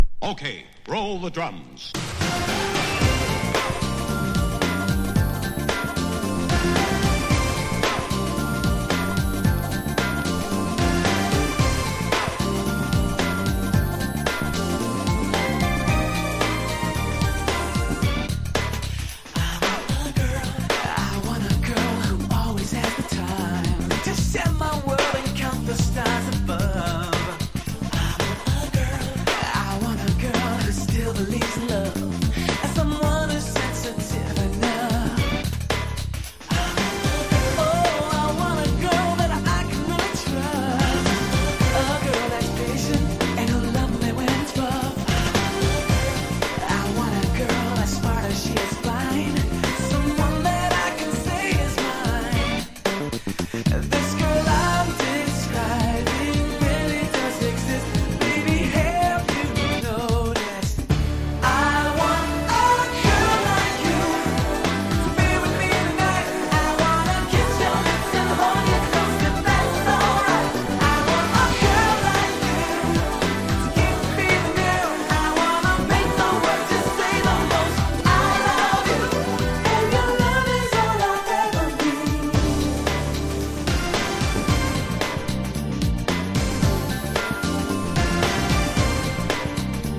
キャッチーなメロディー・ラインがとにかくナイス!!